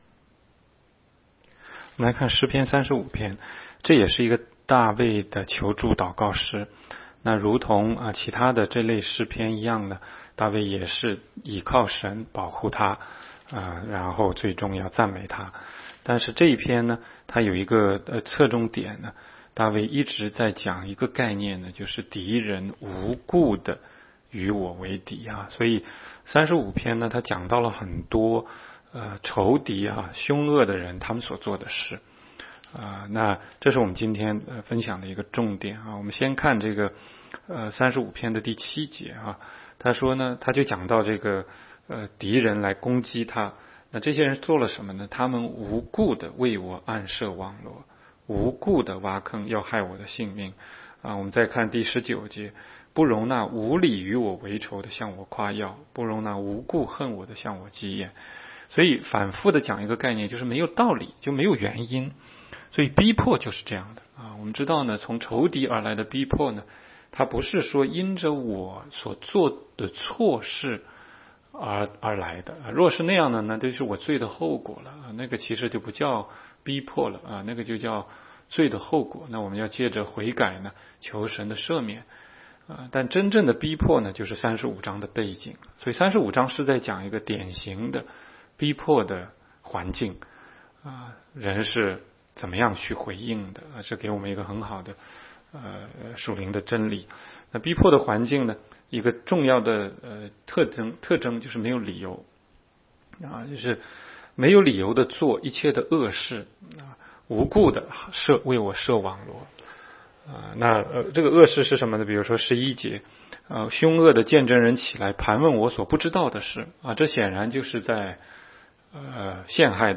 16街讲道录音 - 每日读经-《诗篇》35章